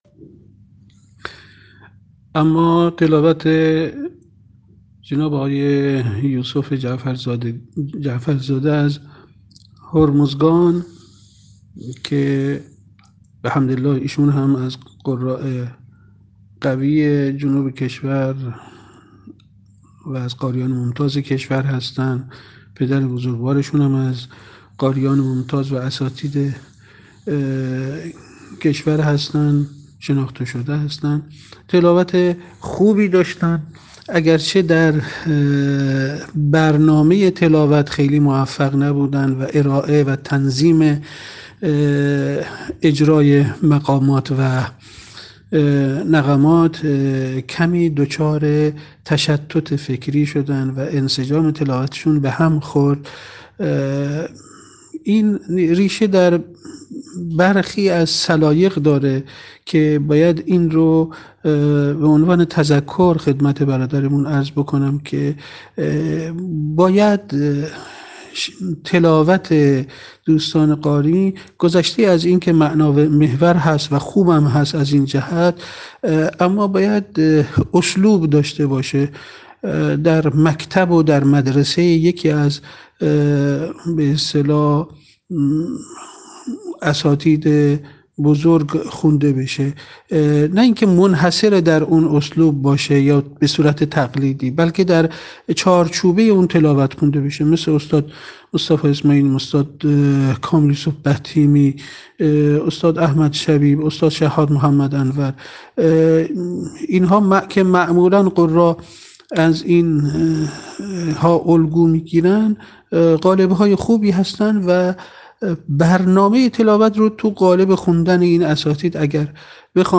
تحلیل تلاوت